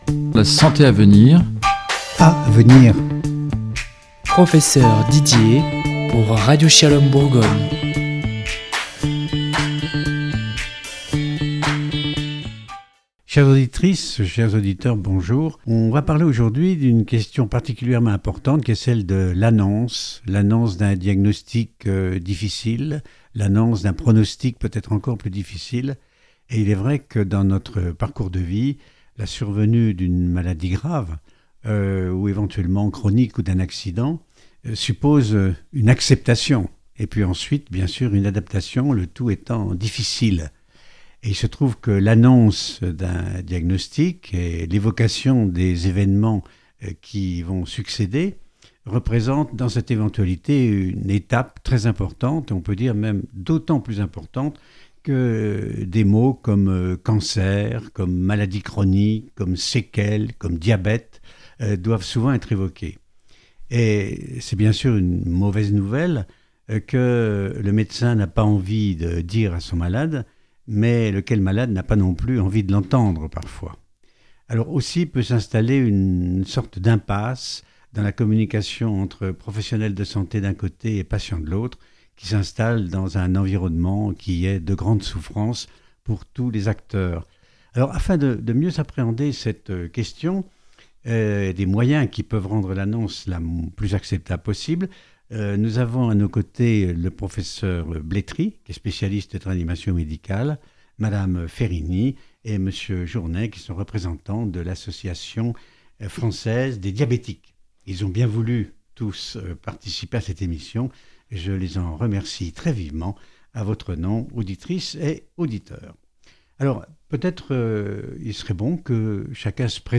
C’est pour mieux appréhender cette question de l’annonce et desréponses possibles à lui donner, que nous avons organisé un atelierassociant un médecin professeur de réanimation médicale et deuxpersonnes porteuses de maladies chroniques travaillant au sein del’association française des diabétiques.